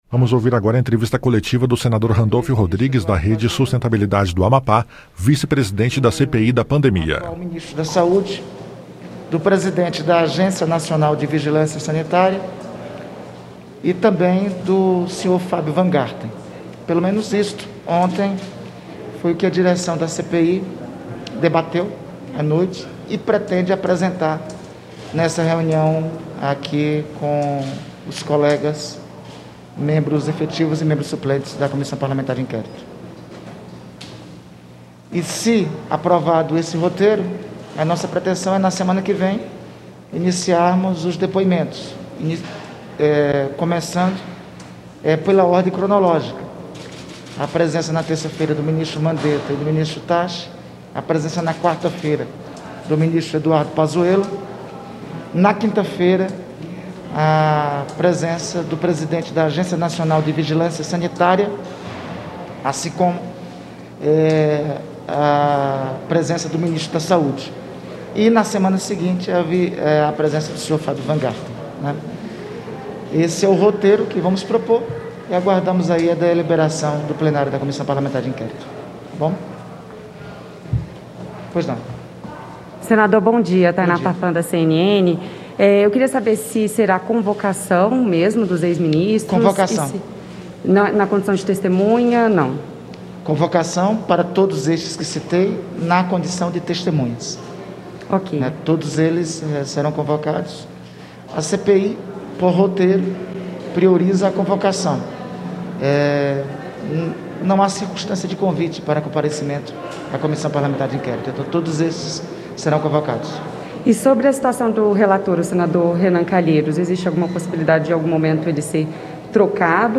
Entrevista coletiva com o vice-presidente da CPI da Pandemia, Randolfe Rodrigues